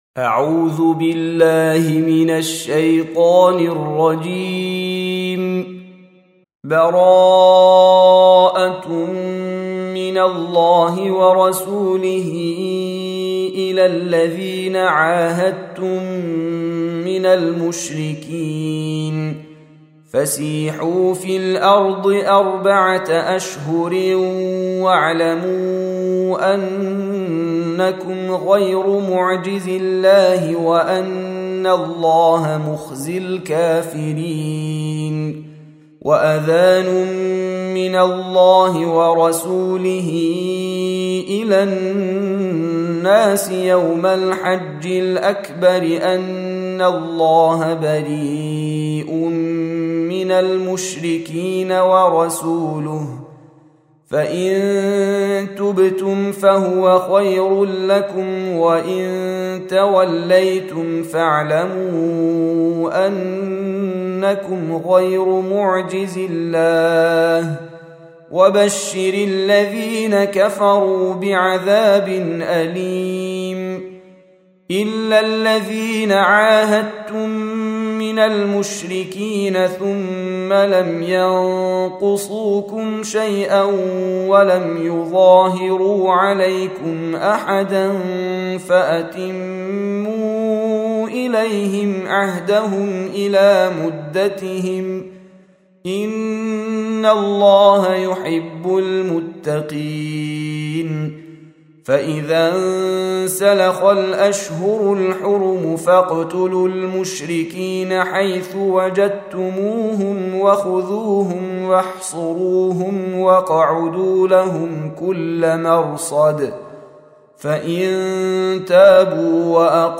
9. Surah At-Taubah سورة التوبة Audio Quran Tarteel Recitation
Surah Repeating تكرار السورة Download Surah حمّل السورة Reciting Murattalah Audio for 9. Surah At-Taubah سورة التوبة N.B *Surah Excludes Al-Basmalah Reciters Sequents تتابع التلاوات Reciters Repeats تكرار التلاوات